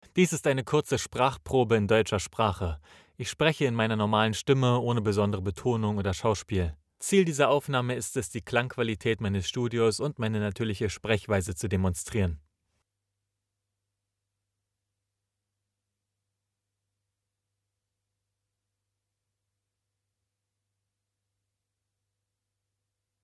Male
Approachable, Bright, Bubbly, Character
I record from my professionally treated home studio, delivering broadcast-ready audio.
Acting Reel.mp3
Microphone: Neumann TLM 103